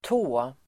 Uttal: [tå:]